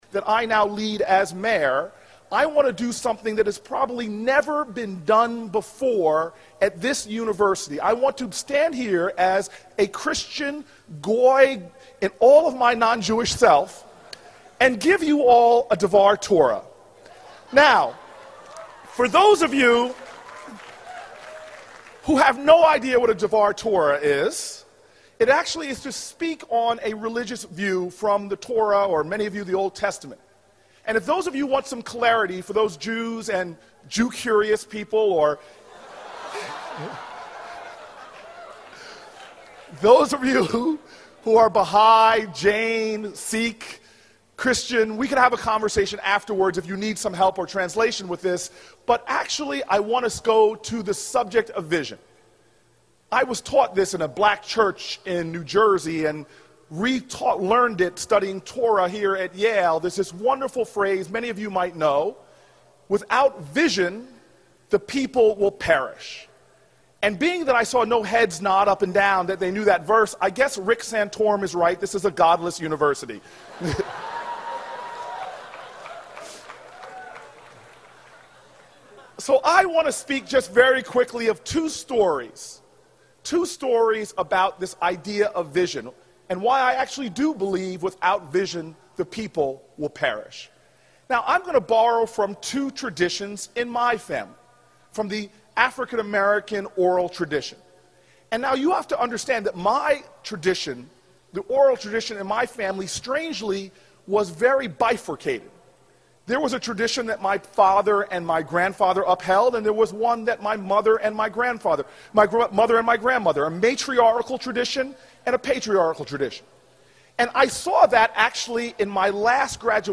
公众人物毕业演讲 第436期:科里布克2013年耶鲁大学(4) 听力文件下载—在线英语听力室